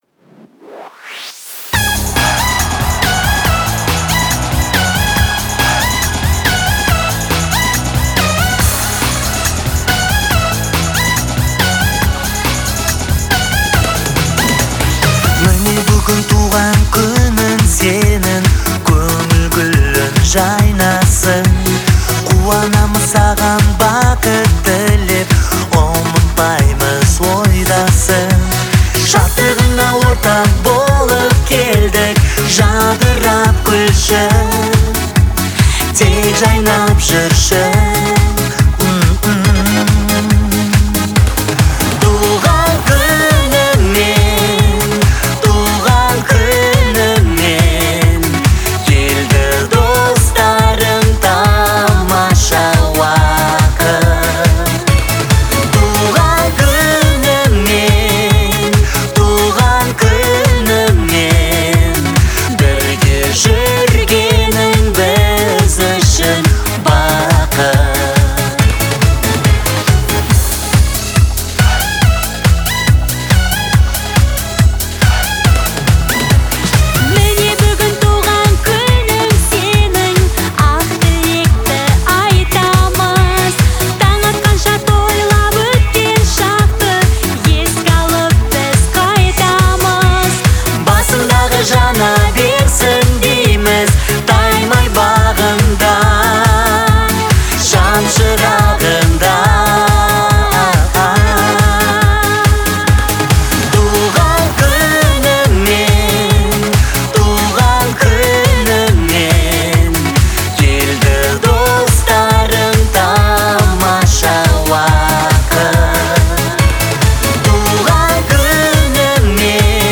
выполненная в жанре поп-фолк.